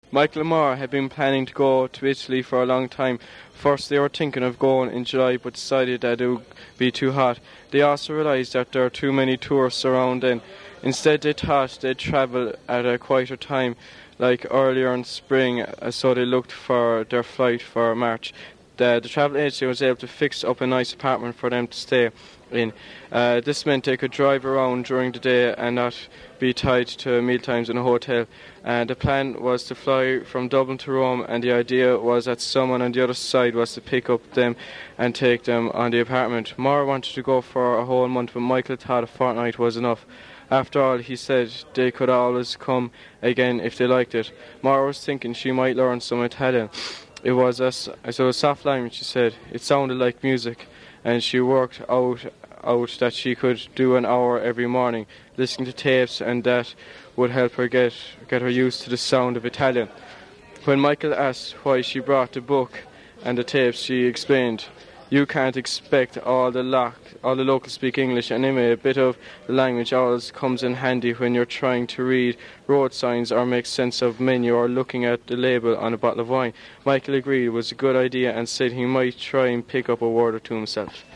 The informants all read a standard (fictitious) text which I offered to them when doing recordings.
Local speaker from Co. Kerry, south-west of Ireland